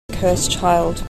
I pointed out in my post and video that the final t of monosyllabic cursed may be lost before child, make it sound the same as curse. And curse child is exactly what we hear from J. K. Rowling herself:
curse_child_rowling.mp3